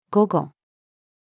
午後-female.mp3